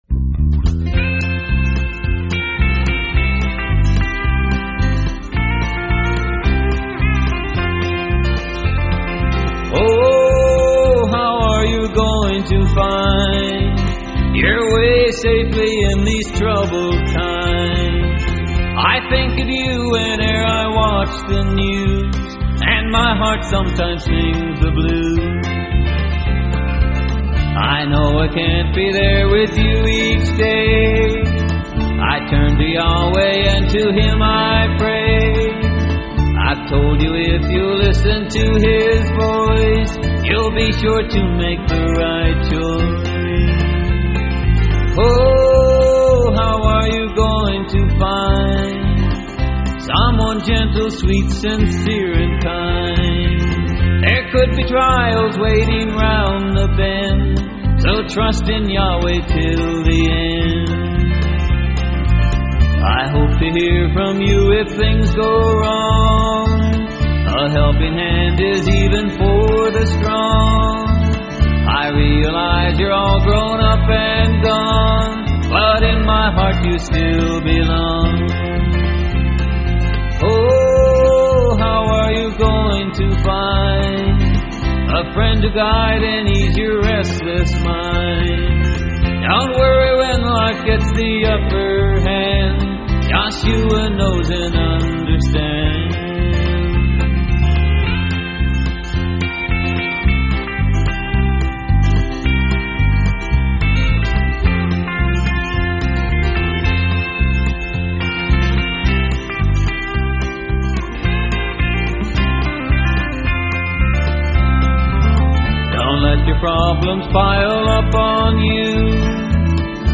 (Genre: Country)